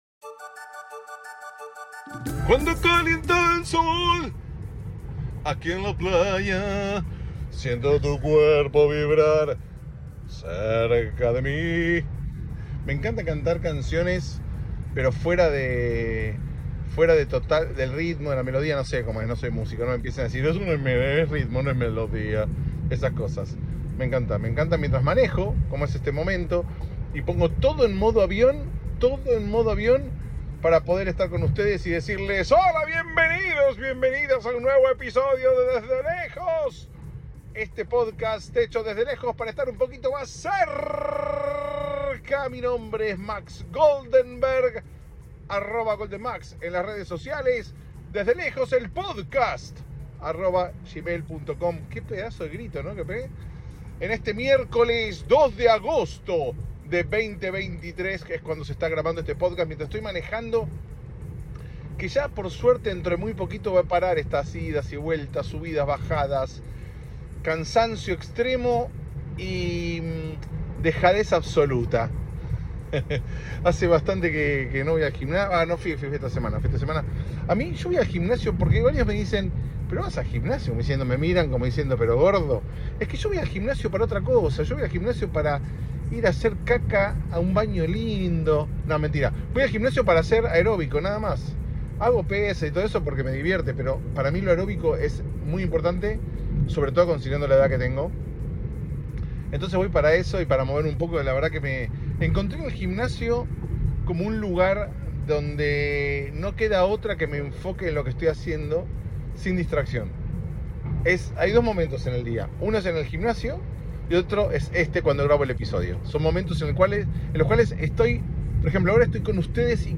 Grabado en la ruta (una vez más) un episodio bien íntimo (?) O algo así...